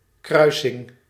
Ääntäminen
IPA: /i.bʁid/